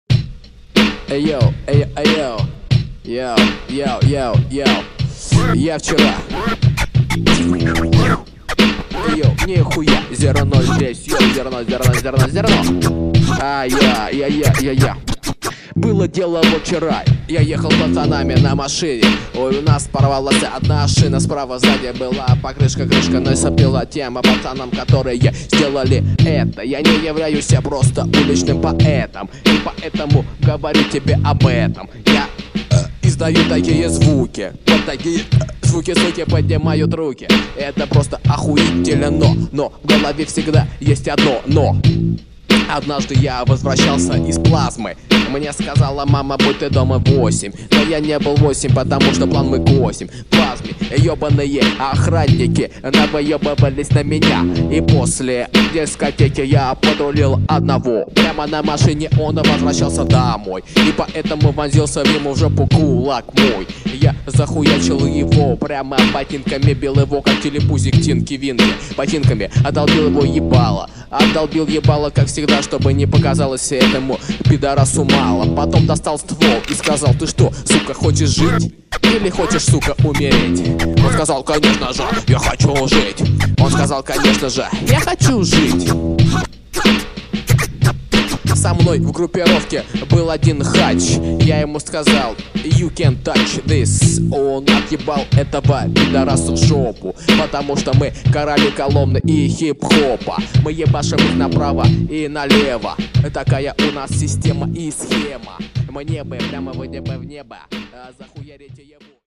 Рэп (46715)